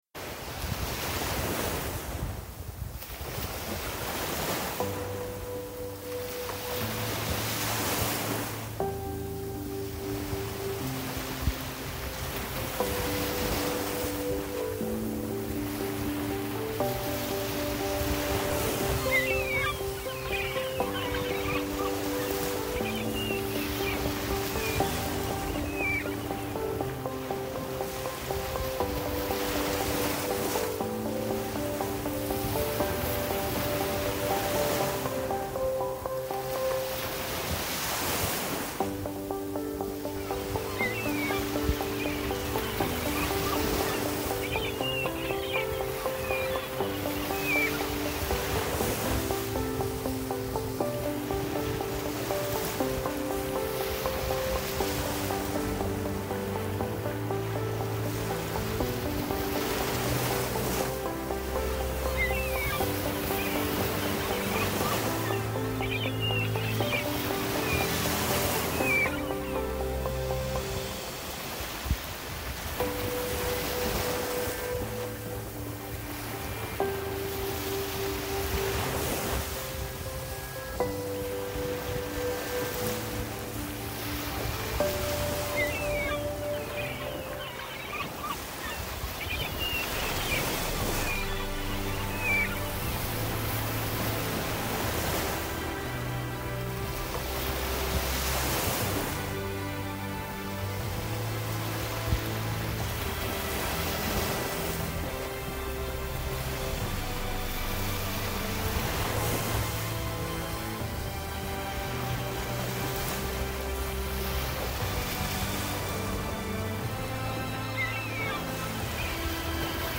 Hay que ver las fotografías con esta banda sonora,
mar-musica-gaviotas1.mp3